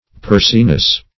Pursiness \Pur"si*ness\, n. State of being pursy.